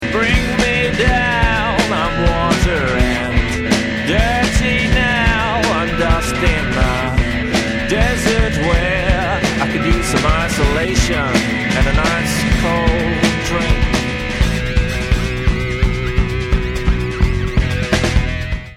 147k MP3 (18 secs, mono)